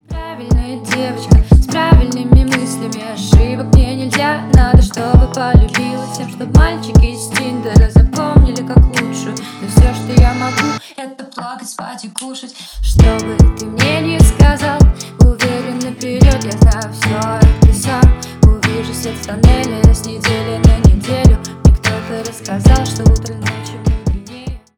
Рок Металл
грустные # спокойные # тихие